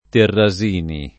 [ terra @& ni ]